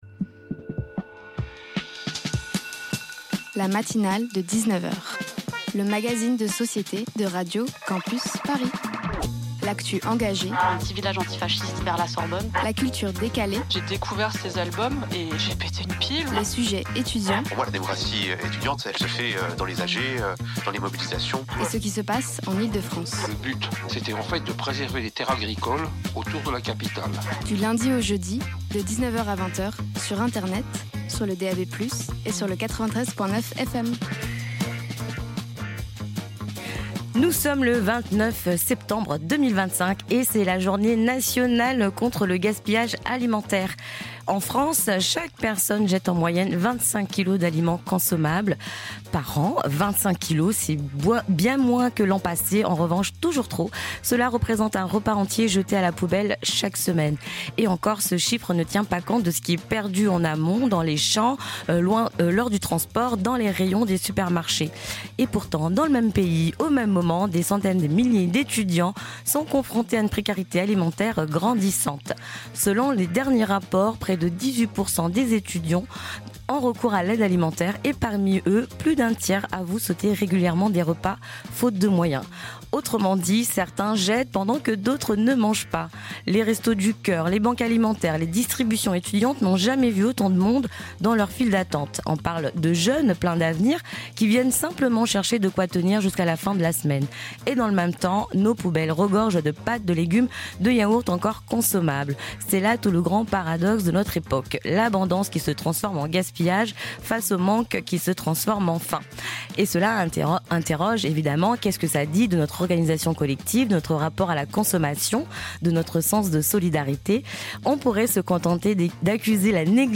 Tous les soirs, des reportages pas chiants, des chroniques épiques et des interviews garanties sans conservateur viendront ponctuer cette heure où l’ennui subit le même sort que Bonaparte : le bannissement.